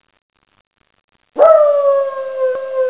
Howl1
HOWL1.wav